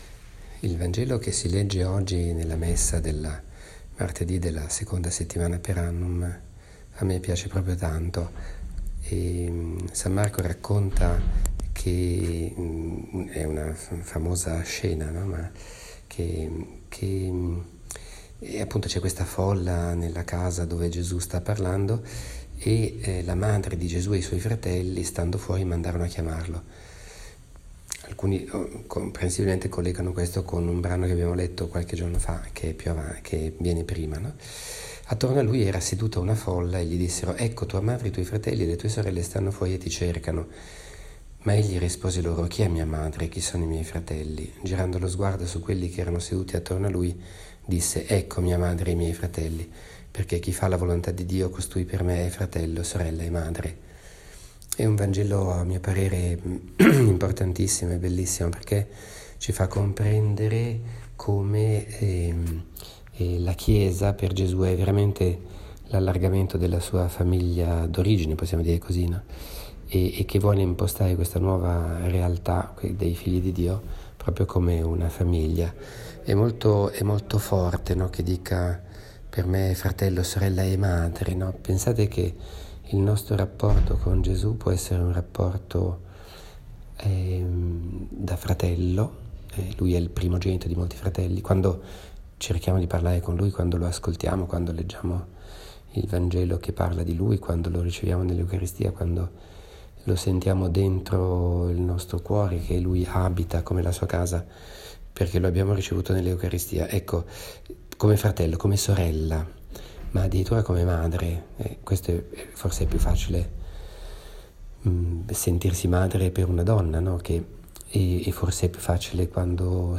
Commento al vangelo (Mc 3,31-35) del 23 gennaio 2018, martedì della III settimana del Tempo Ordinario.